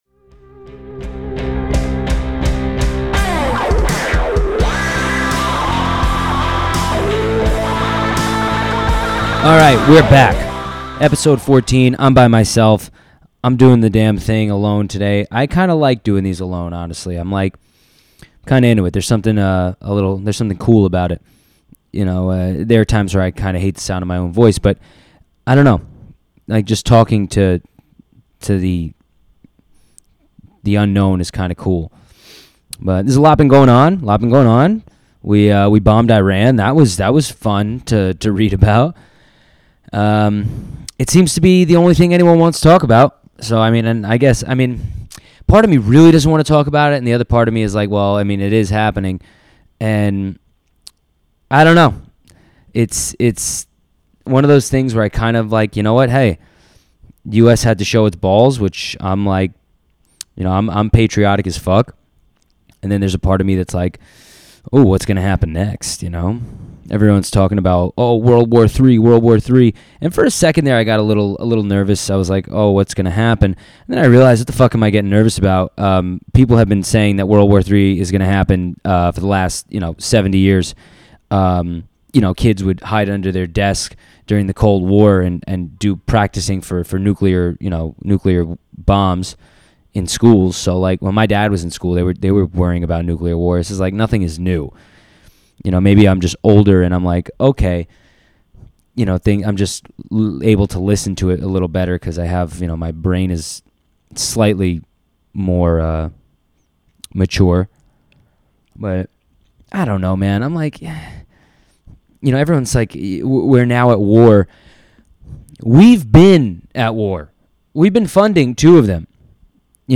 Another solo podcast.